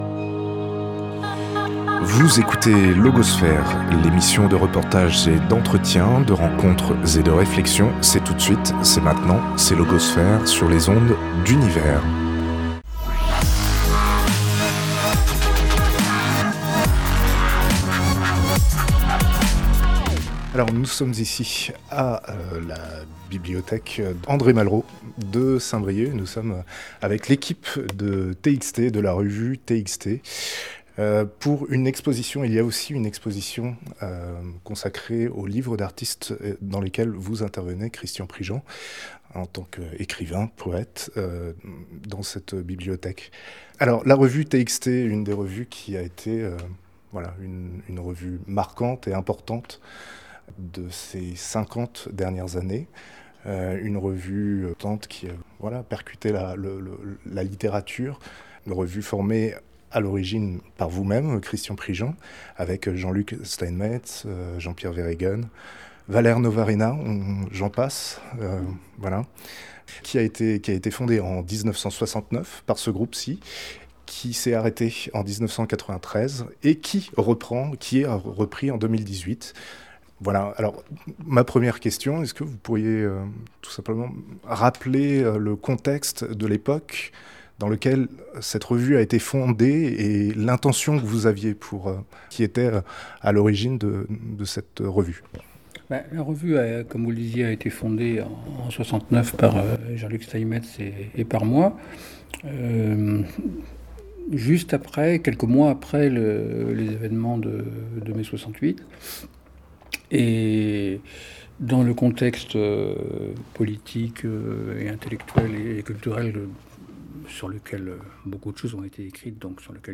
Entretien avec les auteurs Christian Prigent